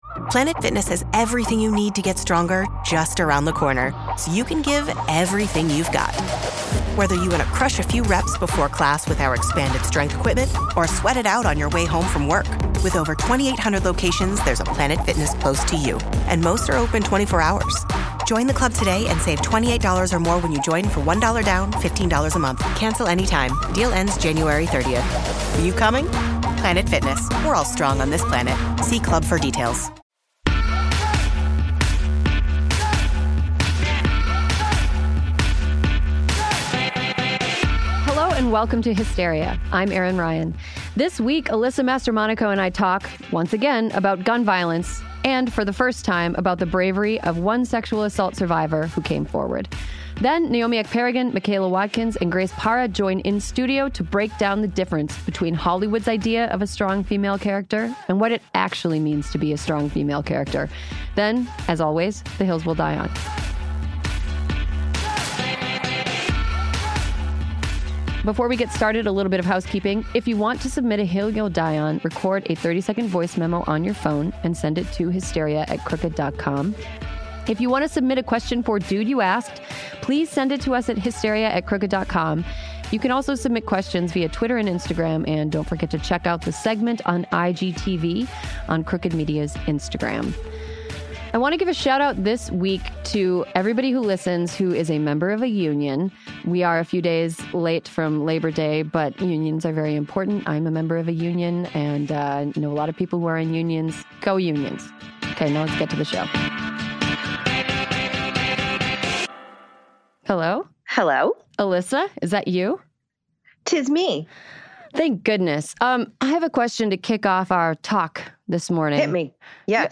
join in studio to break down the difference between Hollywood's idea of a strong female character and what it actually means to be strong. Then, as always, the hills we'll die on.